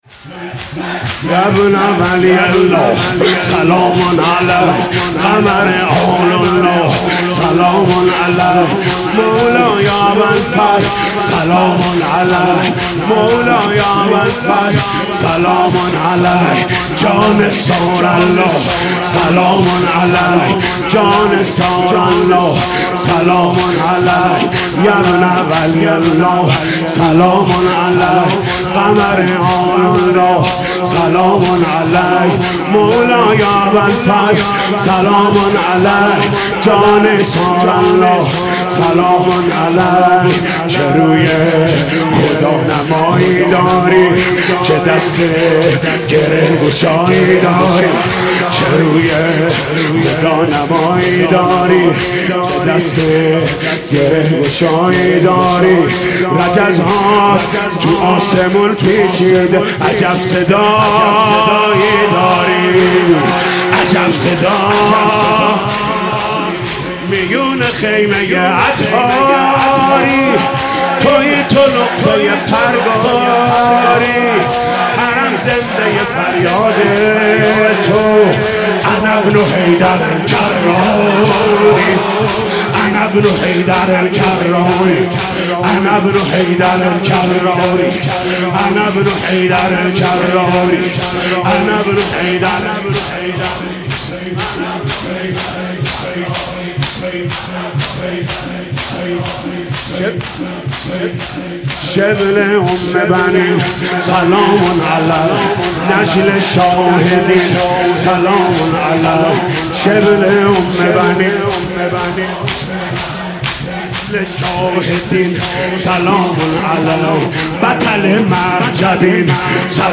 مداحی و نوحه
(شور)